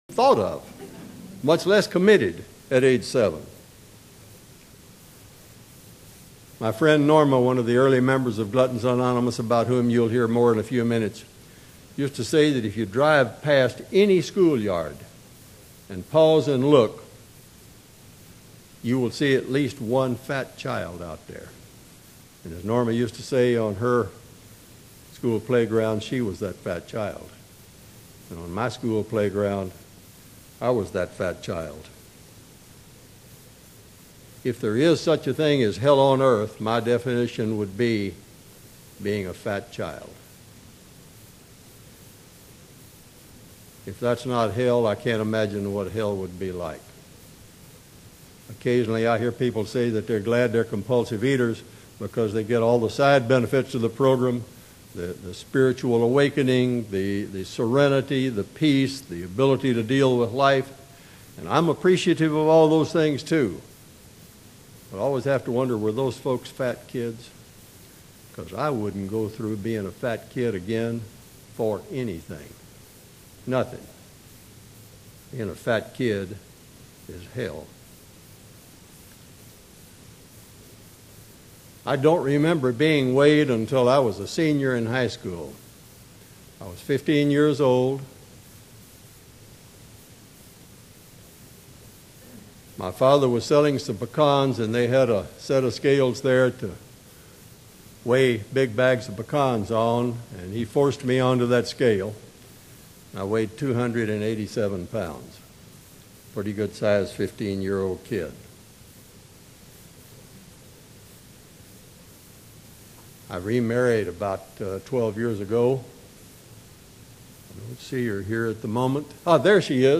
Speaker Podcasts & Audio Files
Region 1 Convention, Seattle 2001